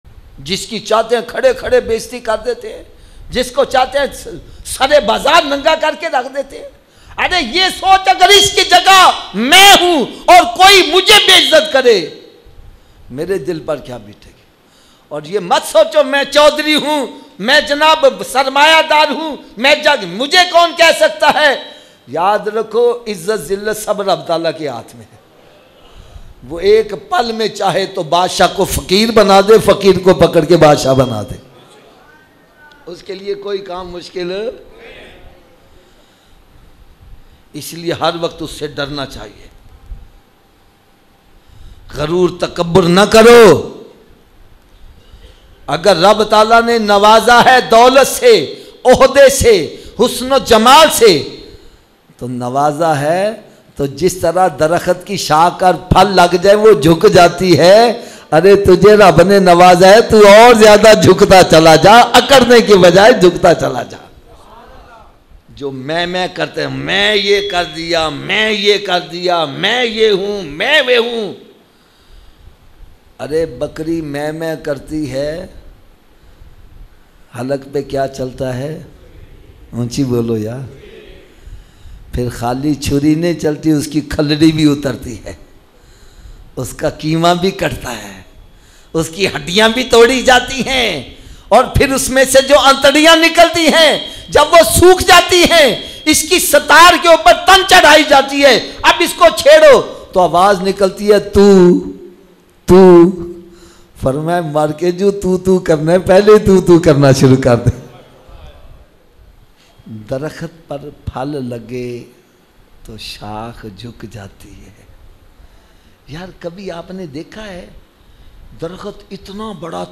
Yaad Rakho Izzat Zillat Allah Ke Haath Mein Hai Emotional Bayan